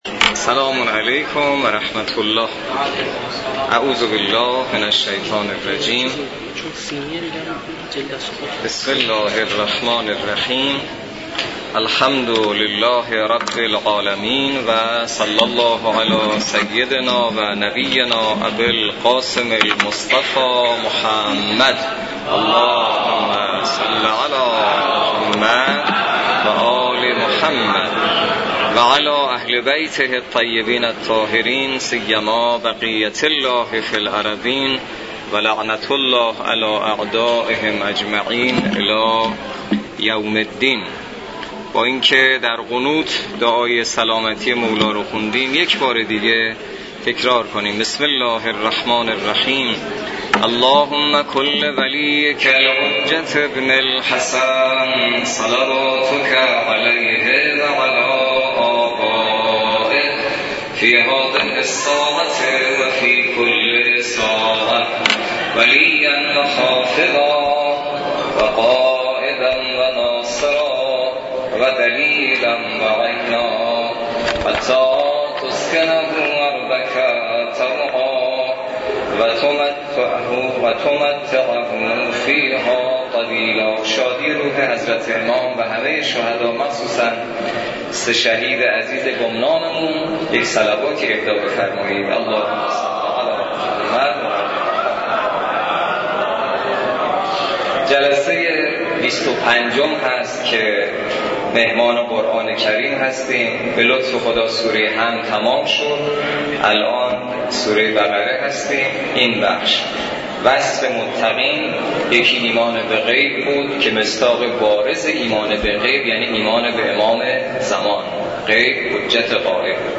برگزاری هفتمین جلسه تفسیر سوره مبارکه بقره توسط امام جمعه کاشان در مسجد دانشگاه.
هفتمین جلسه تفسیر سوره مبارکه بقره توسط حجت‌الاسلام والمسلمین حسینی نماینده محترم ولی فقیه و امام جمعه کاشان در مسجد دانشگاه کاشان برگزار گردید.